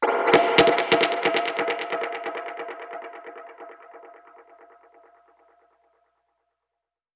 Index of /musicradar/dub-percussion-samples/134bpm
DPFX_PercHit_B_134-08.wav